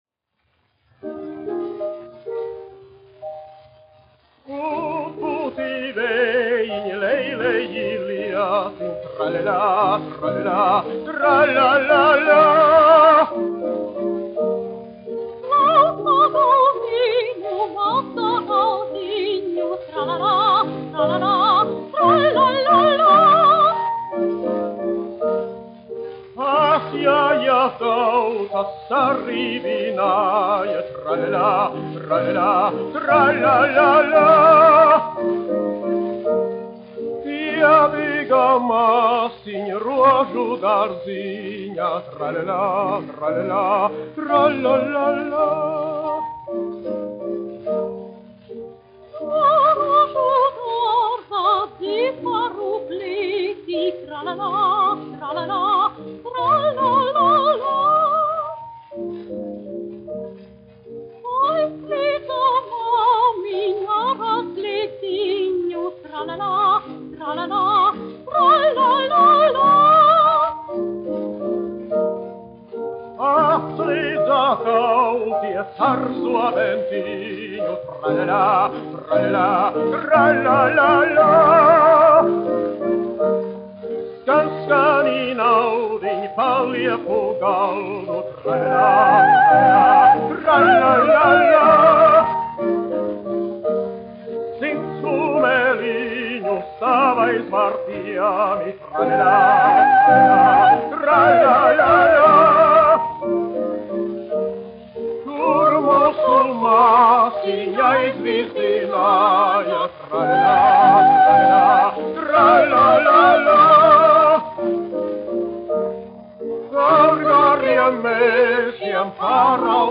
Jāzeps Vītols, 1863-1948, aranžētājs
1 skpl. : analogs, 78 apgr/min, mono ; 25 cm
Latviešu tautasdziesmas
Vokālie dueti ar klavierēm
Skaņuplate